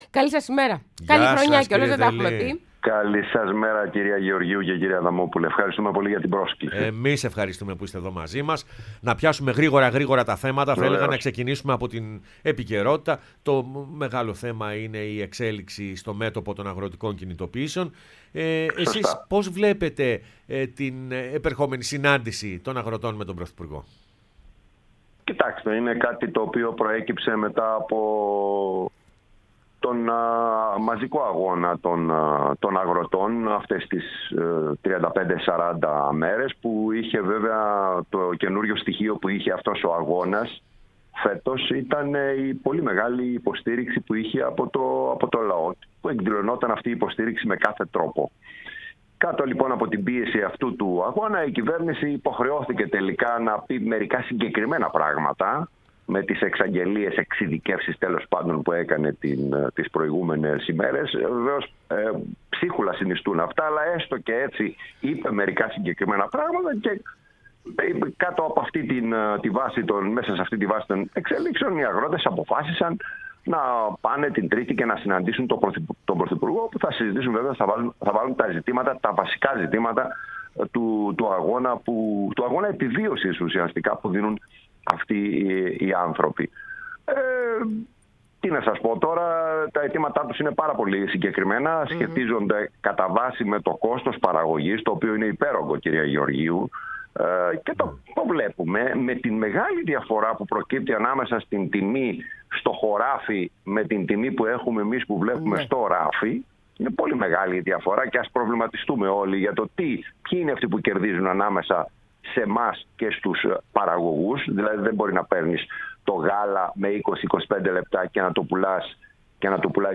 Γιάννης Δελής, βουλευτής ΚΚΕ Θεσ/νίκης, μίλησε στην εκπομπή Πρωινές Διαδρομές